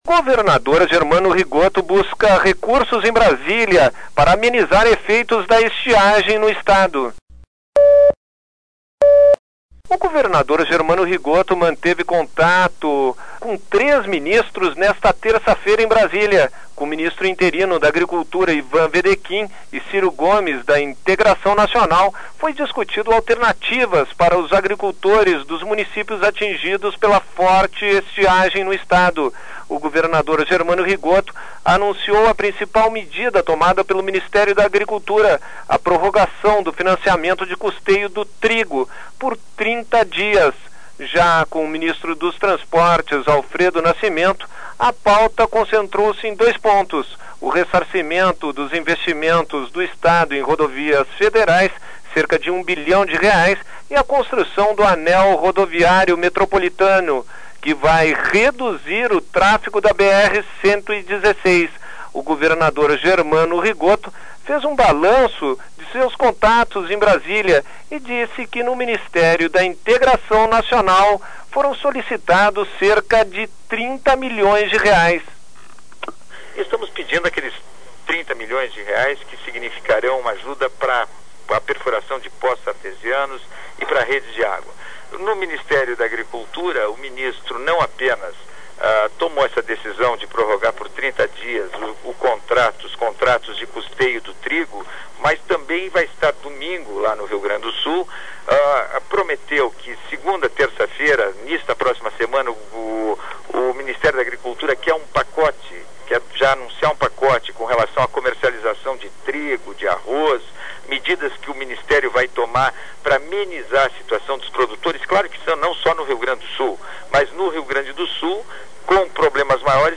O governador Germano Rigotto fez um balanço da sua ida à Brasília. Rigotto manteve reuniões buscando recursos para amenizar a seca no Estado, discutindo ainda a necessidade da construção do anel rodoviário metropolitano, para reduzir o tráfego na BR 116.